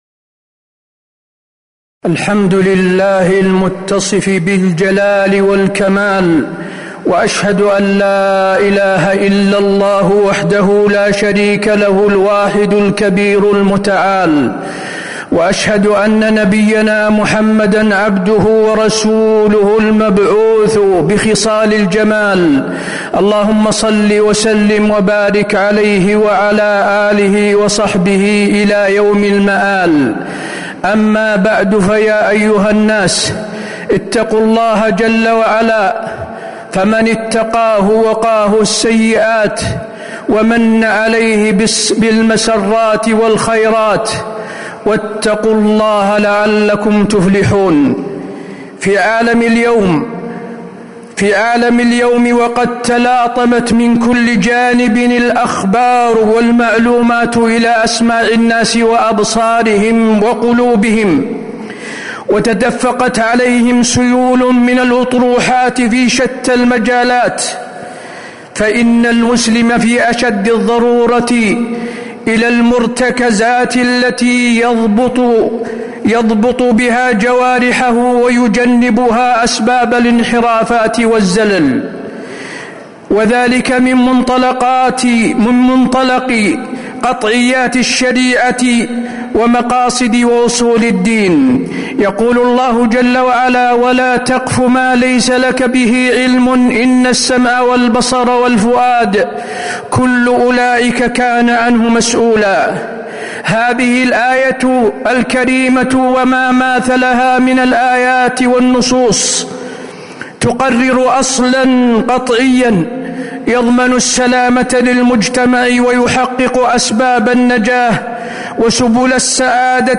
تاريخ النشر ١٧ ربيع الأول ١٤٤٦ هـ المكان: المسجد النبوي الشيخ: فضيلة الشيخ د. حسين بن عبدالعزيز آل الشيخ فضيلة الشيخ د. حسين بن عبدالعزيز آل الشيخ التحذير من الشائعات